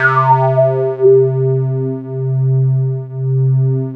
JUP 8 C4 6.wav